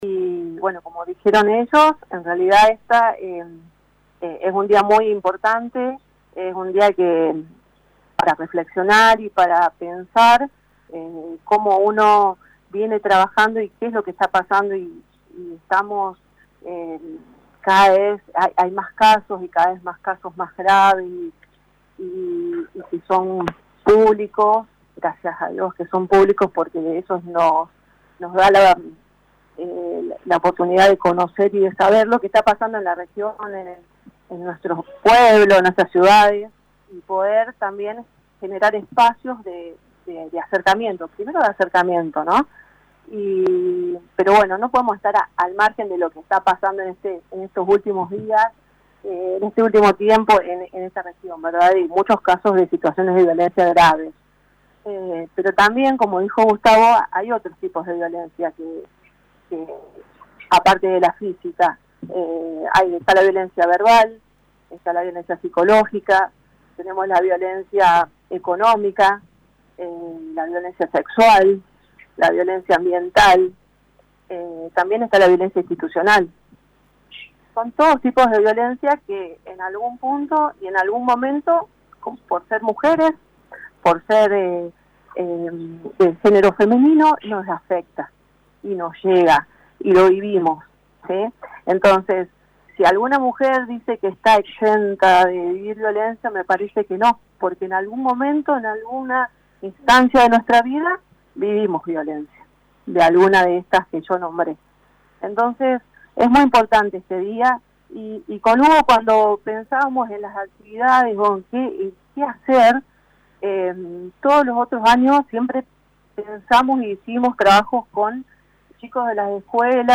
En Brinkmann, desde el Punto Mujer que depende de la Secretaría de Desarrollo Humano y Bienestar Social, se realizó una conferencia de prensa donde se resaltaron los valores de esta fecha importante de conmemorar.